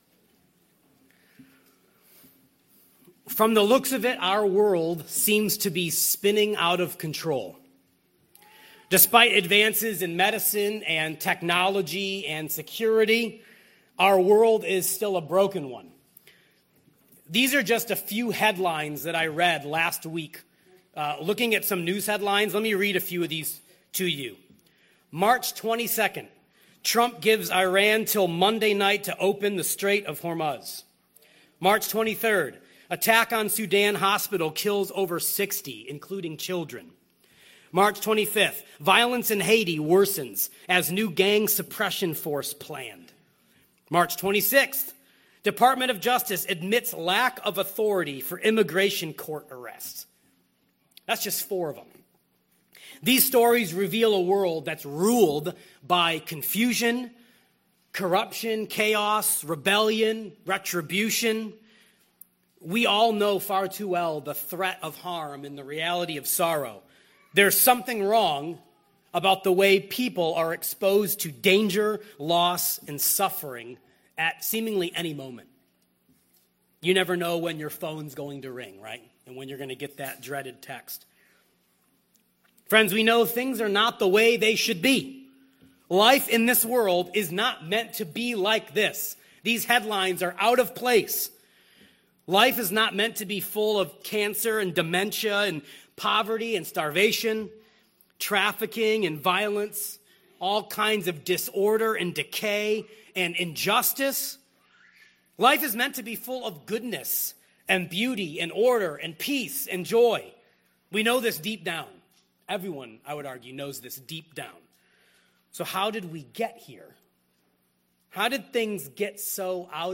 Who Rules the World? (Hebrews 2:5-9) from Emmanuel Community Church Sermons.